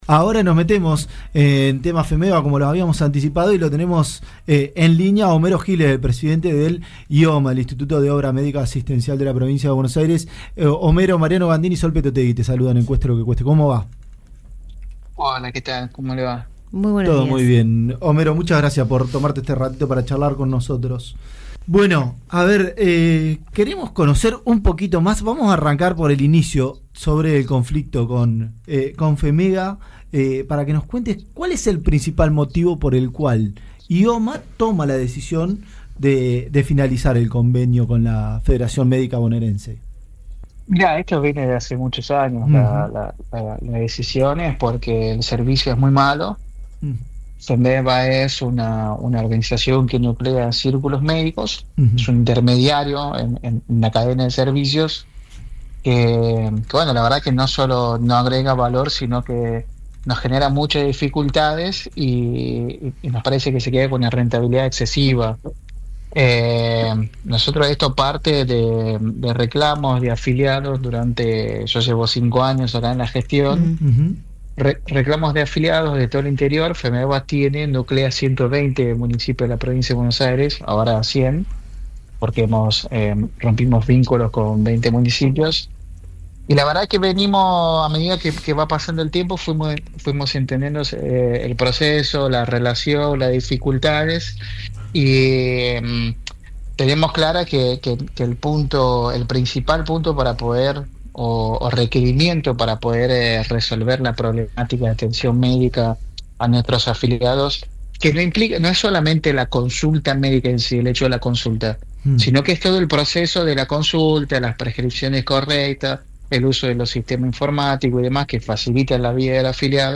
Homero Giles, presidente de IOMA conversó con FM CIUDADES, 96.3, de Ensenada, en el Programa Cueste lo que Cueste. Allí habló de la relación de IOMA con FEMEBA y el flamante convenio que se firmó con el Círculo médico de Junín, para destrabar el conflicto prestacional en esa zona.
Homero-Giles-en-Cueste-lo-que-cueste-Radio-Ciudades-FM-96.3.mp3